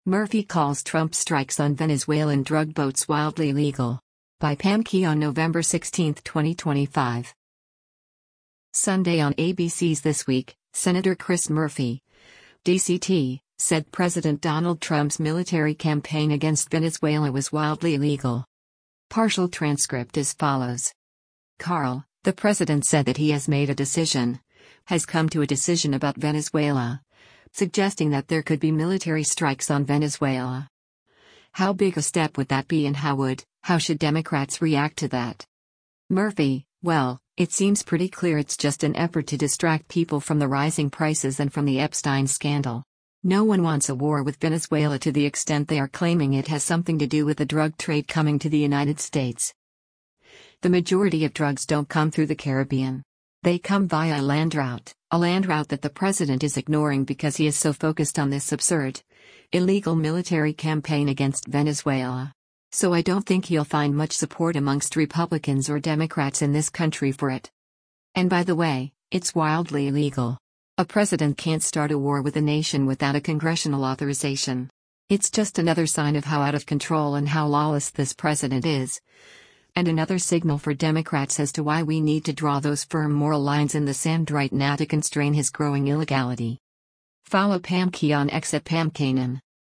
Sunday on ABC’s “This Week,” Sen. Chris Murphy (D-CT) said President Donald Trump’s military campaign against Venezuela was “wildly illegal.”